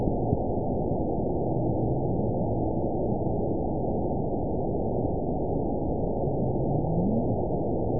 event 920618 date 04/01/24 time 07:03:15 GMT (1 year, 1 month ago) score 8.79 location TSS-AB09 detected by nrw target species NRW annotations +NRW Spectrogram: Frequency (kHz) vs. Time (s) audio not available .wav